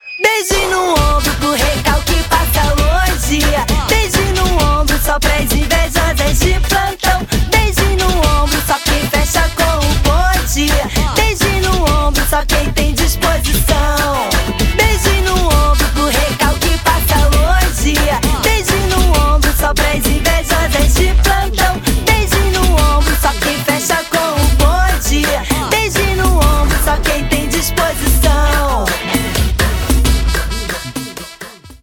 Refrão da música